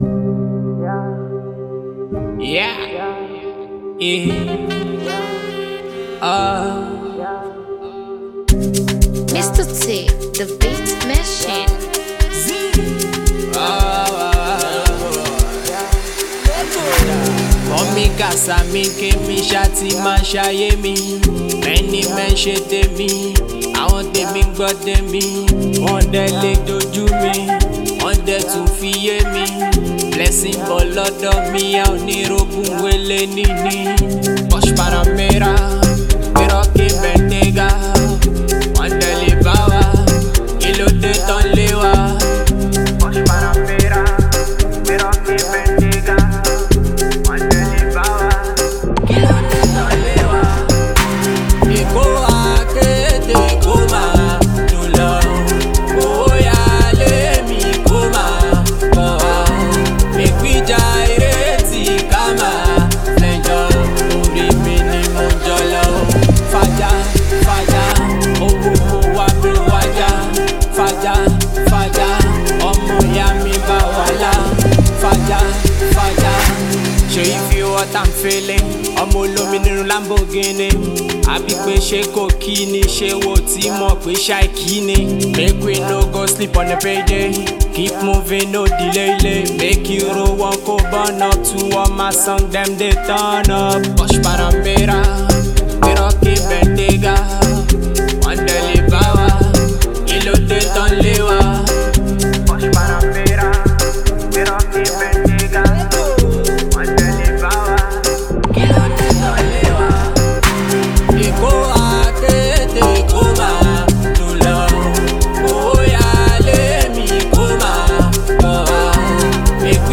Afrobeat and street-pop